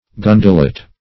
gundelet - definition of gundelet - synonyms, pronunciation, spelling from Free Dictionary Search Result for " gundelet" : The Collaborative International Dictionary of English v.0.48: Gundelet \Gun"de*let\, n. [Obs.]
gundelet.mp3